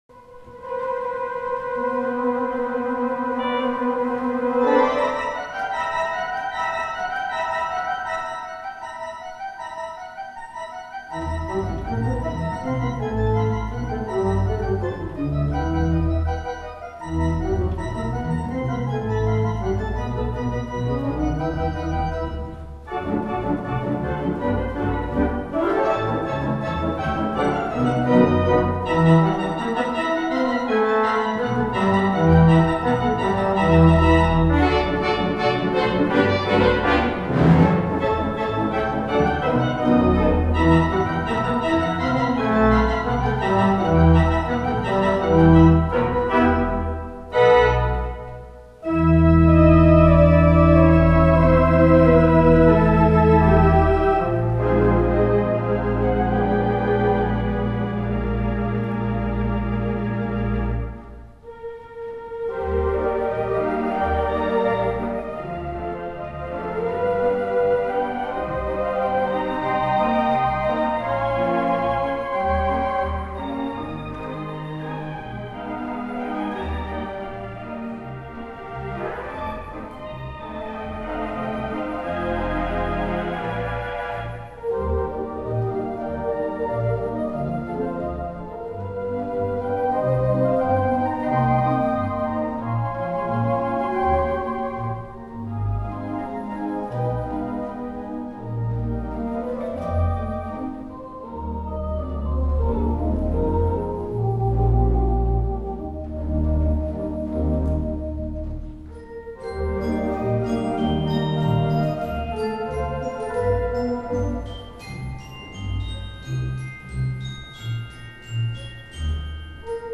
4/33 Mighty Möller Theatre Pipe Organ